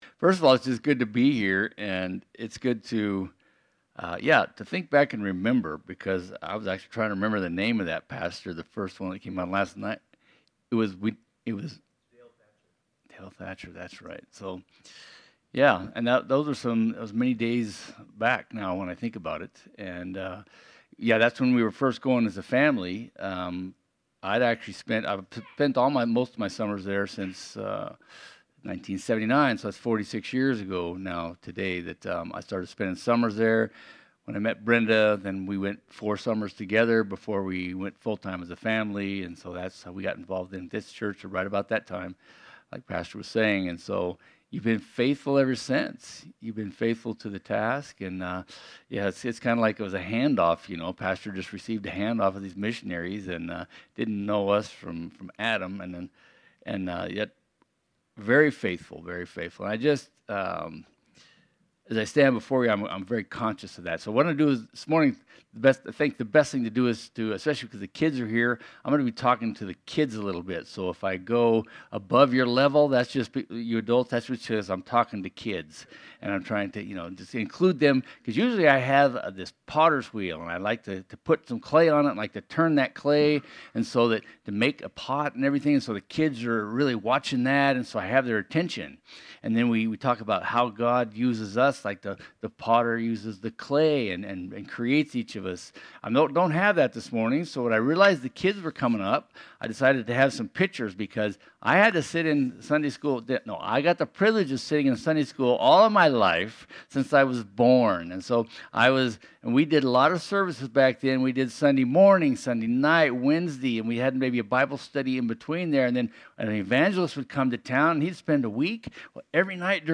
Passage: Matthew 28:16-20, Isaiah 52:7 Service Type: Sunday Studies